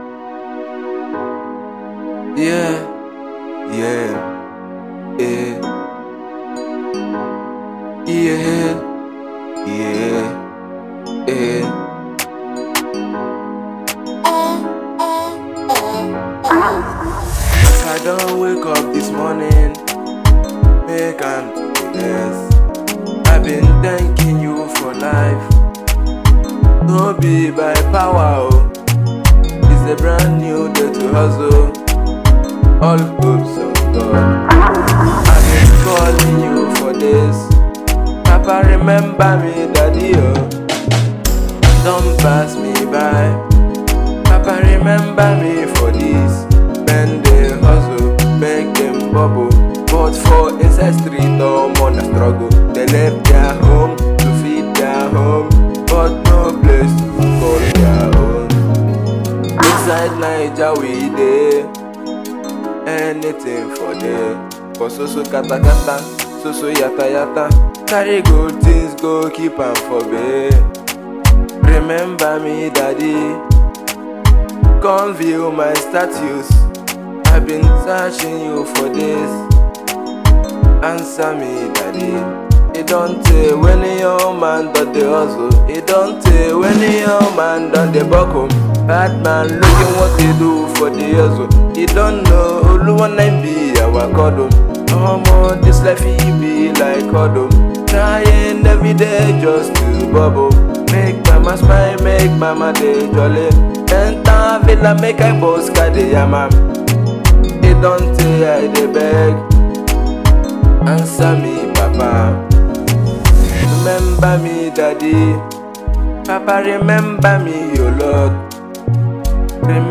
Afrobeats and Gospel